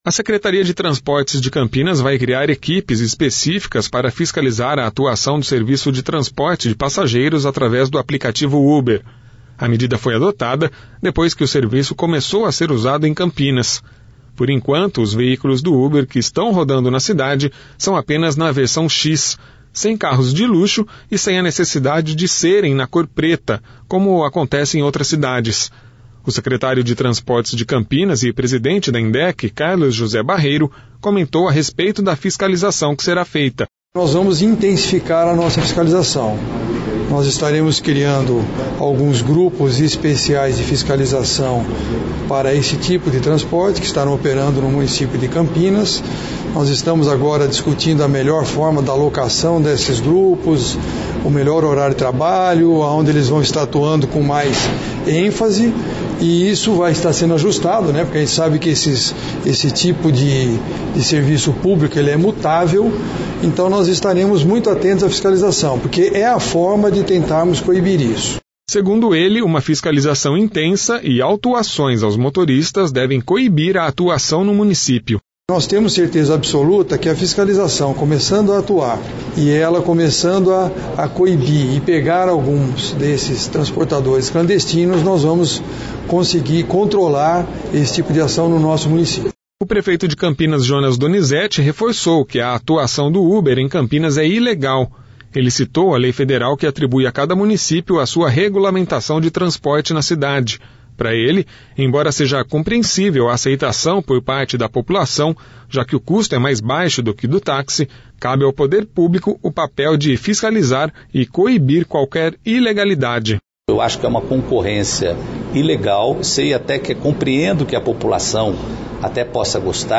O Secretário de Transportes de Campinas e Presidente da Emdec, Carlos José Barreiro comentou a respeito da fiscalização que será feita.
O Prefeito de Campinas, Jonas Donizette, reforçou que a atuação do Uber em Campinas é ilegal.